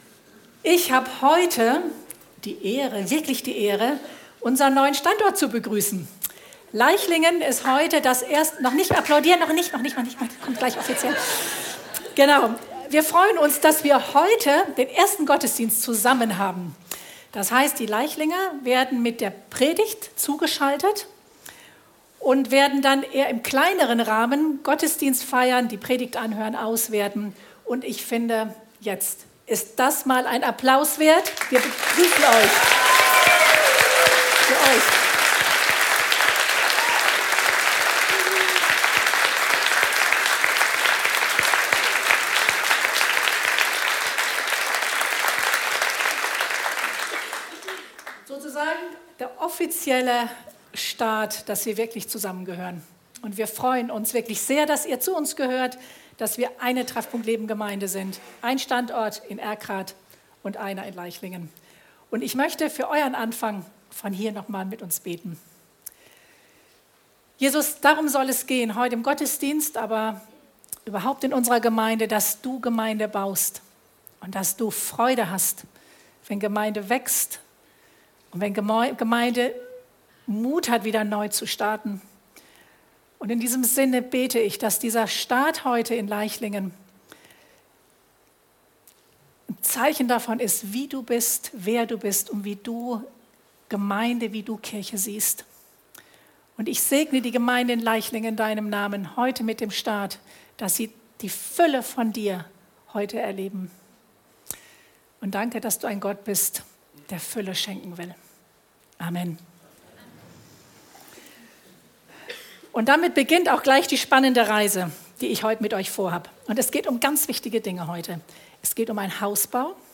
Predigten der Treffpunkt Leben Gemeinde, Erkrath